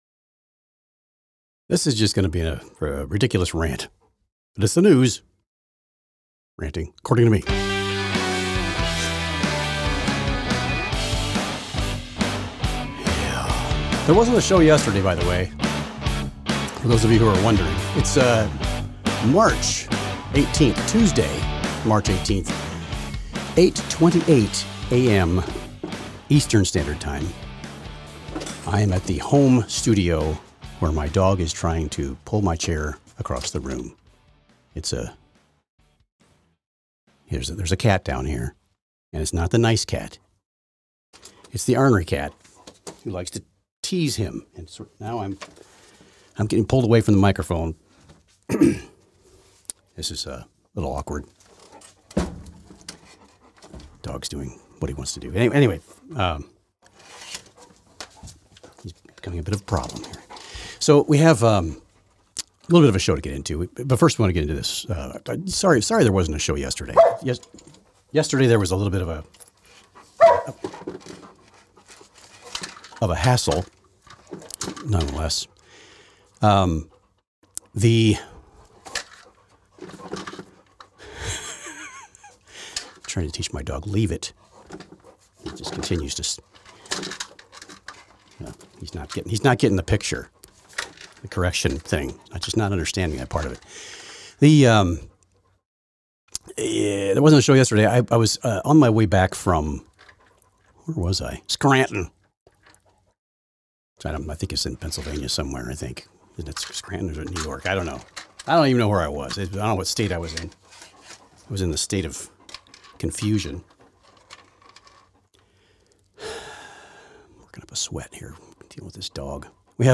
My rant on the issues. The News According to Me!